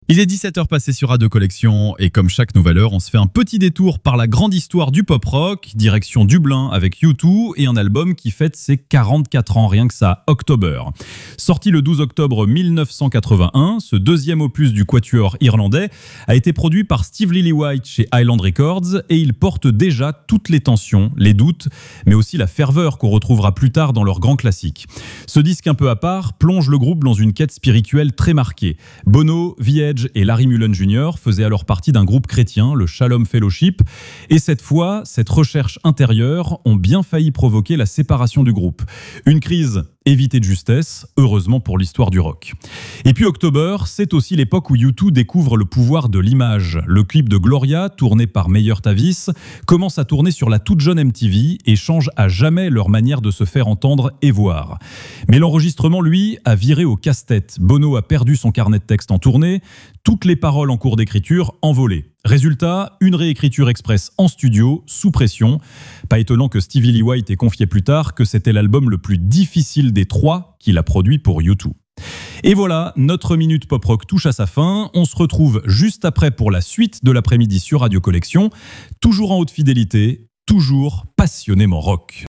Vous écoutez la chronique Pop Rock de Radio Collection, la webradio gratuite et sans pub qui diffuse les plus grands classiques et les nouveautés en qualité Hi-Fi.